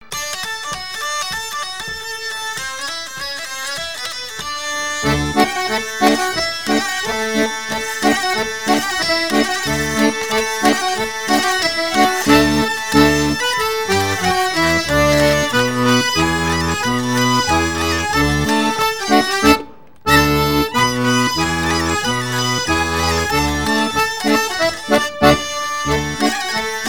danse : orsay
Pièce musicale éditée